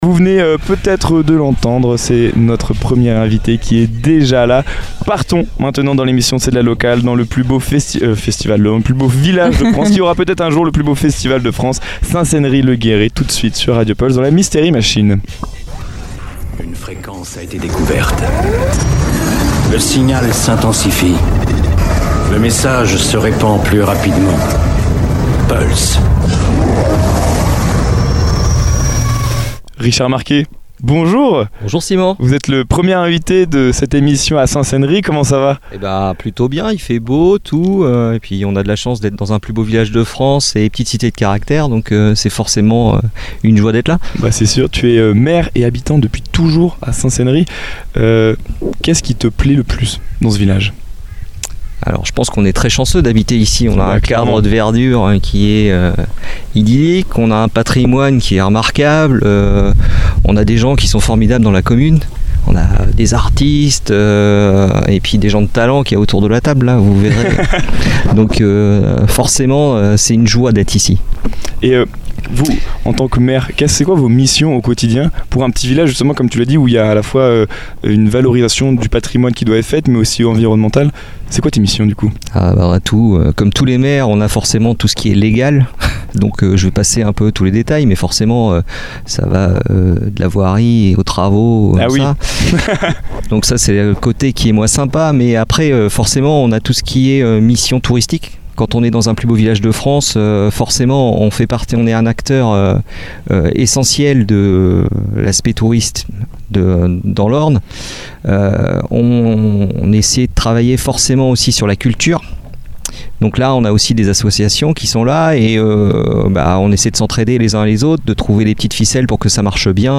Dans cet épisode du Mystery Machine Summer Tour, nous faisons escale à Saint-Céneri-le-Gérei, l’un des plus beaux villages de France, pour rencontrer son maire, Richard Marquet. Au micro, il nous parle de l’identité singulière de la commune, de son patrimoine remarquable et de son attractivité touristique. Il évoque les enjeux liés à la préservation du site, l’équilibre entre accueil des visiteurs et qualité de vie des habitants, ainsi que les projets portés par la municipalité.